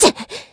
Ripine-Vox_Attack4_kr.wav